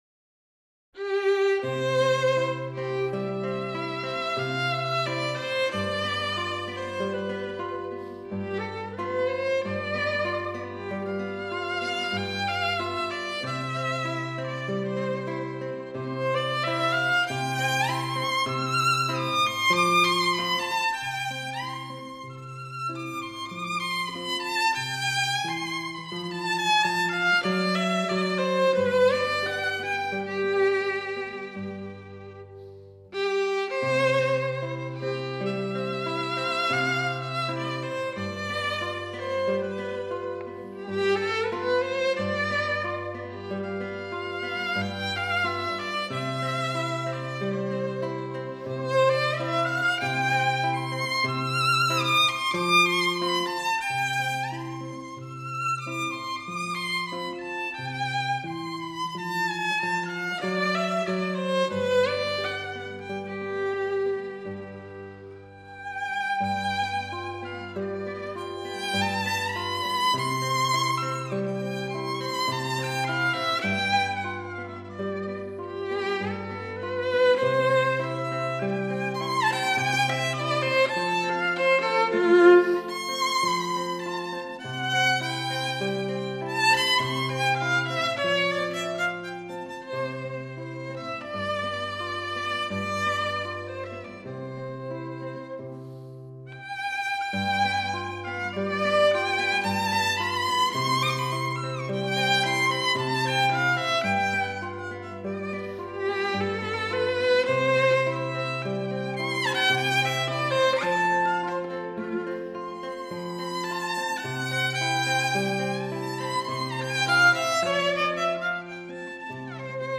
室内乐